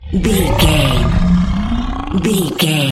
A large and terrifying monster scream.,
Baull Large monster roars-12
Sound Effects
angry
aggressive